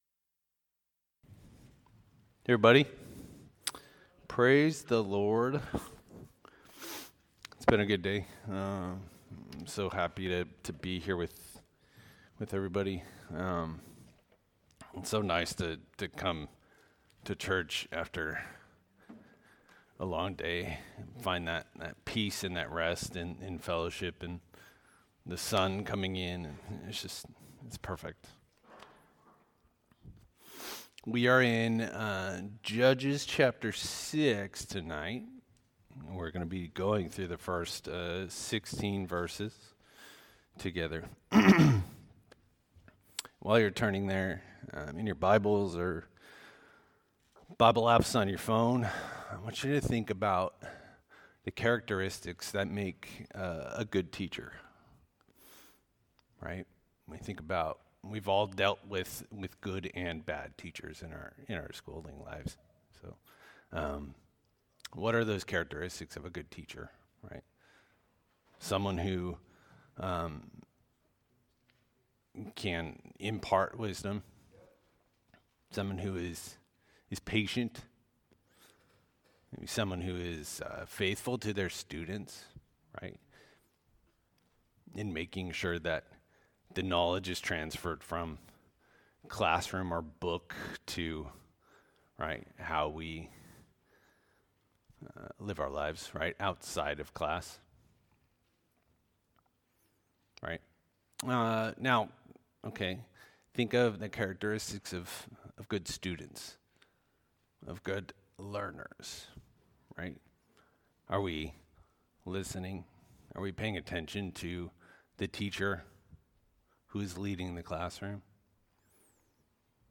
Sermons - Calvary Chapel Eureka